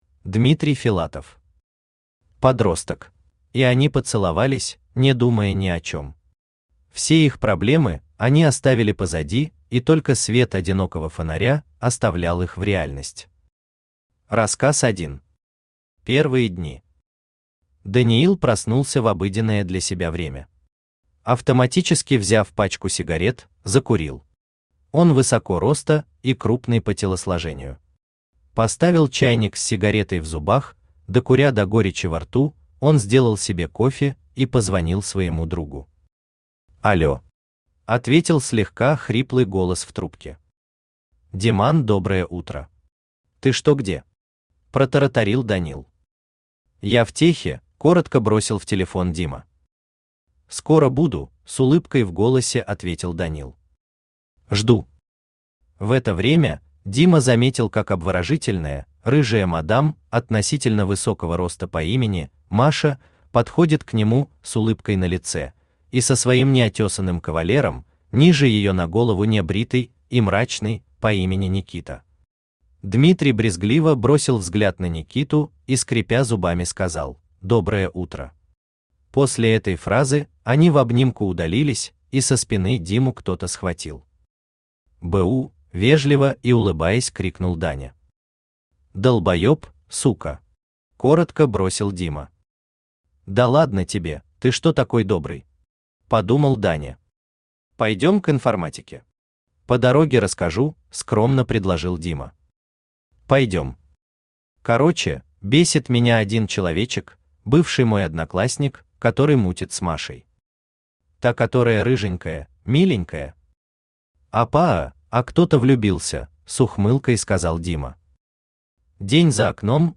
Аудиокнига Подросток | Библиотека аудиокниг